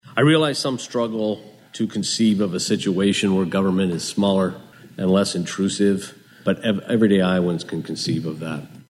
IOWA DEPARTMENT OF MANAGEMENT DIRECTOR KRAIG PAULSEN, WHO LED THE GROUP, SAYS THE REVIEW PANEL IS RECOMMENDING THE CONSOLIDATION OR ELIMINATION OF ONE-HUNDRED-11STATE BOARDS OR COMMISSIONS.